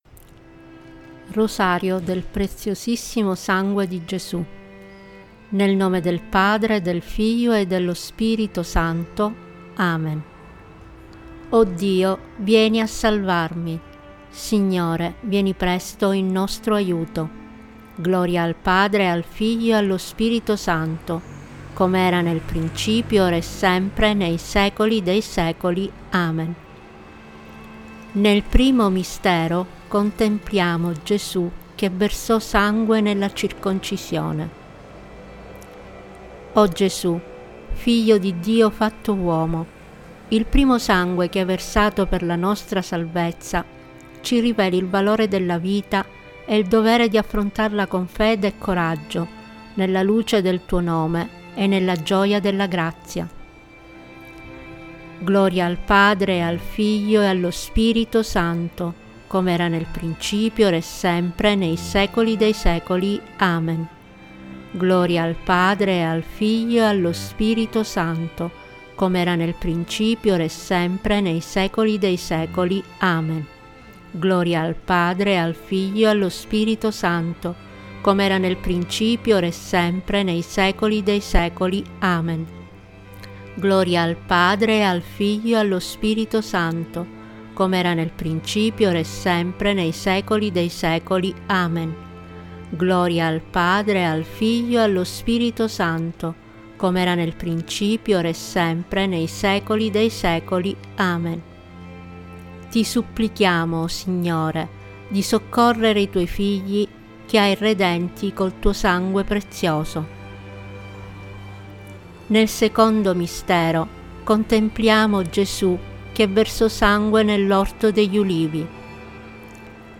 Il rosario del Preziosissimo Sangue di Gesù, con voce guida, si recita in ogni momento dell'anno con particolare fervore per tutto il mese di Luglio ...
Rosario-del-Preziosissimo-Sangue-audio-e-musica-Tempo-di-preghiera.mp3